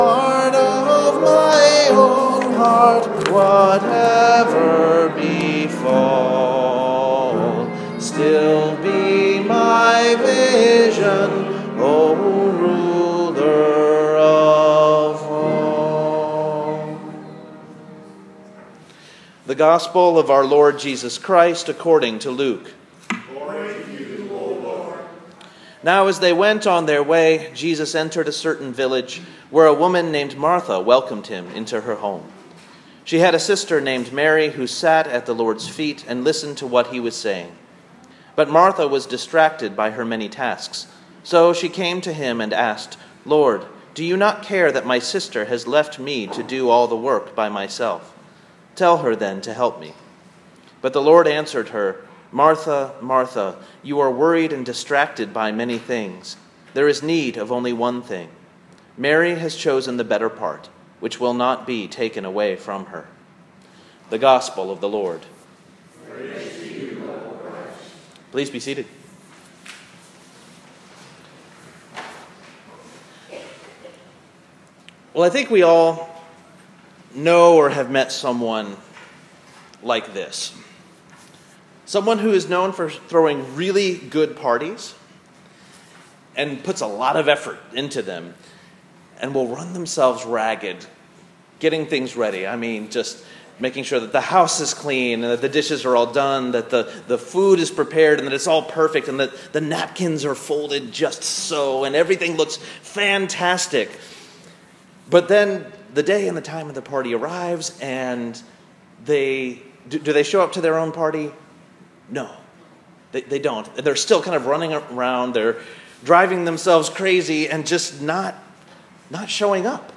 This week's sermon recording from North Presbyterian Church, Kalamazoo:
sermon-pentecost-8-c-2016.m4a